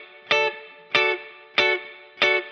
DD_TeleChop_95-Dmaj.wav